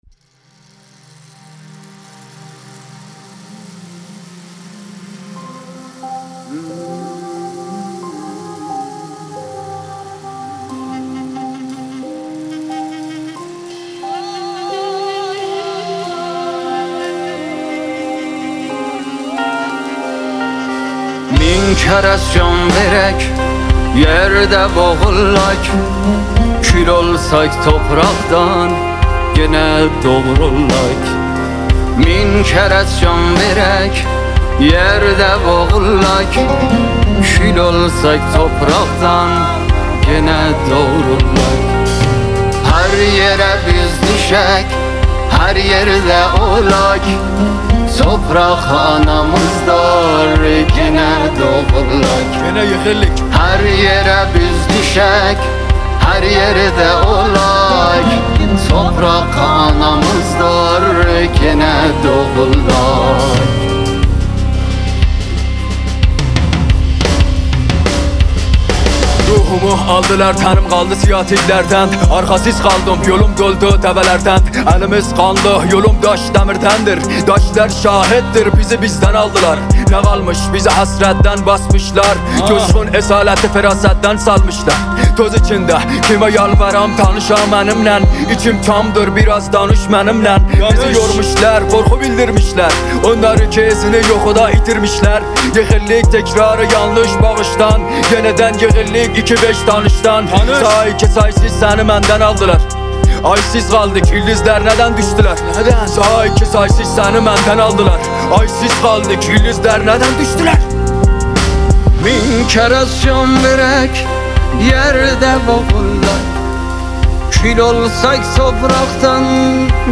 رپ قشقایی
ترکیبی از موسیقی مدرن و ملودی‌های سنتی ترکی
آهنگ عاشقانه ترکی